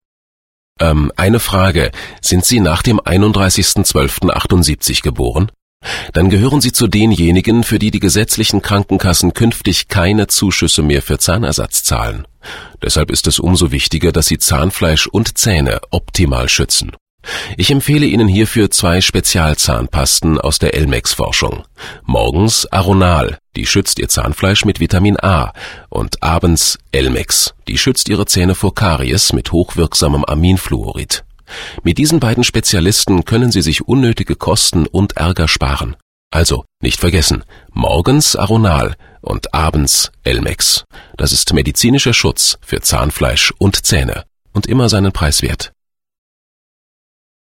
deutscher Profi-Sprecher mit einer großen Leidenschaft für Dokumentationen, Hörbücher und Dialekte
Sprechprobe: Sonstiges (Muttersprache):
german voice over artist